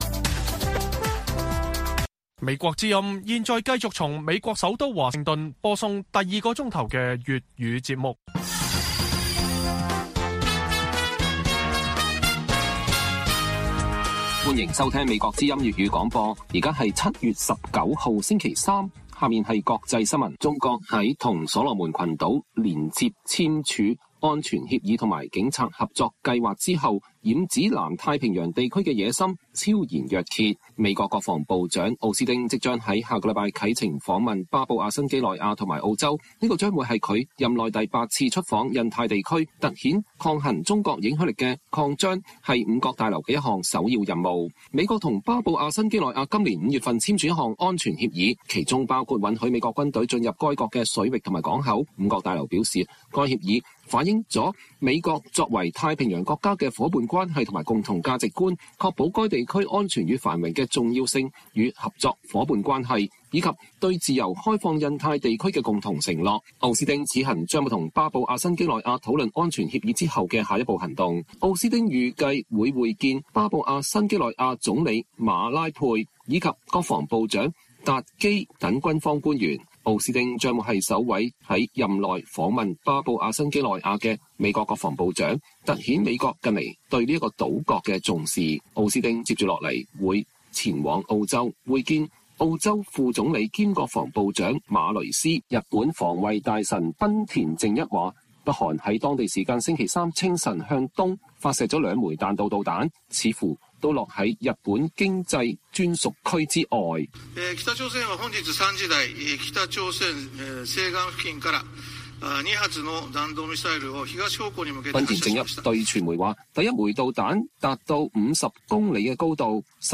粵語新聞 晚上10-11點： 專家指賴清德過境美國獲得的規格與待遇可顯示美國對華發出的信號